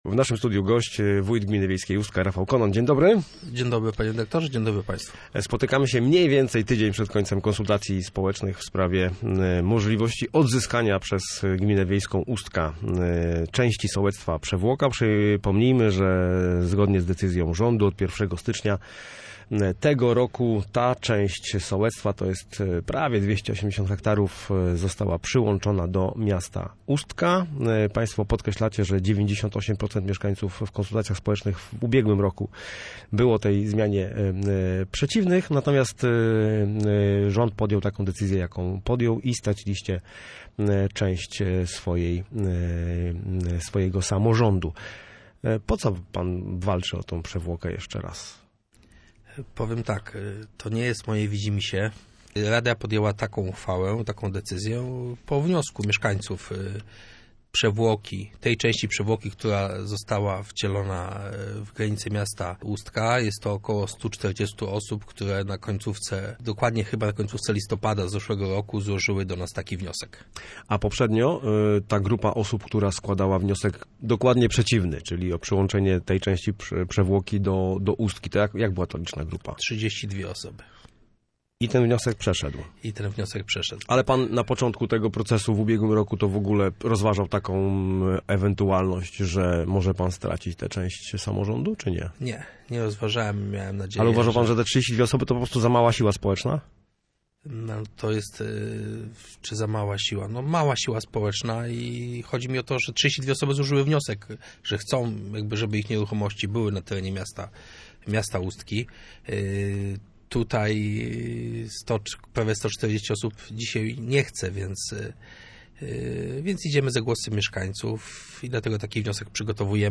Chcemy odzyskać utracone tereny – mówi wójt gminy wiejskiej Ustka.
Konon_gosc_OK.mp3